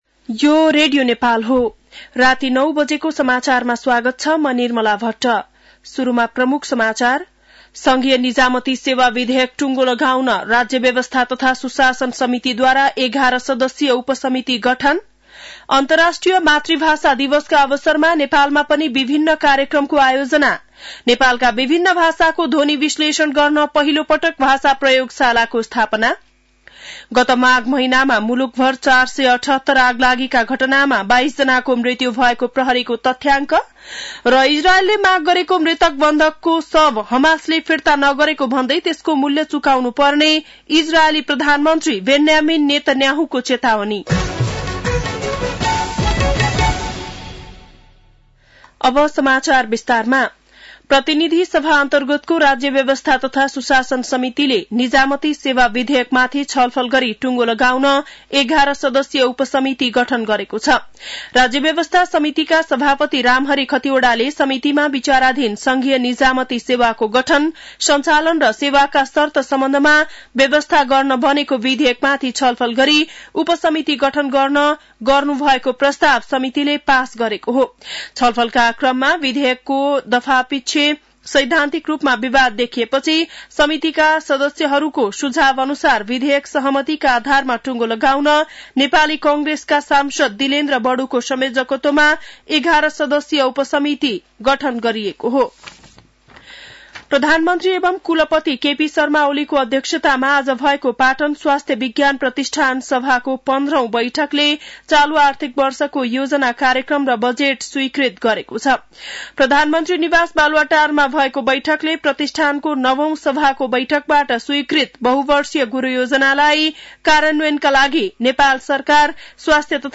बेलुकी ९ बजेको नेपाली समाचार : १० फागुन , २०८१